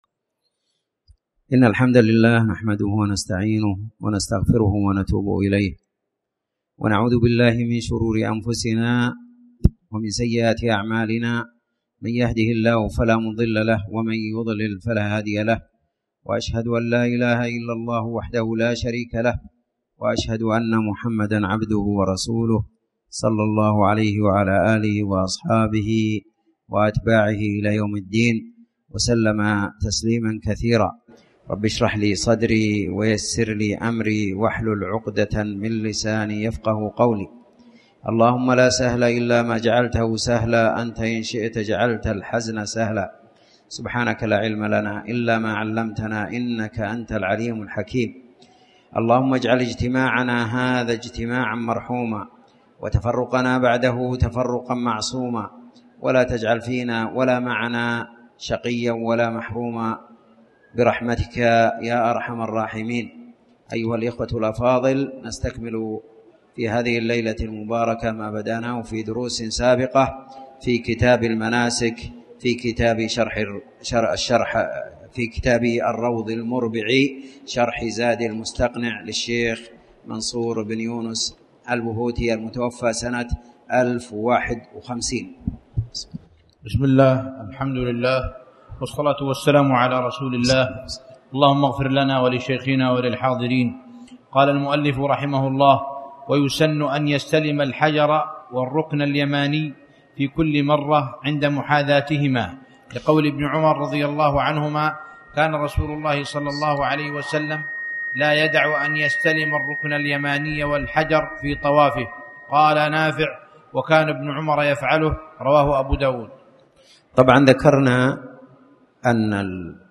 تاريخ النشر ٢٦ محرم ١٤٣٩ هـ المكان: المسجد الحرام الشيخ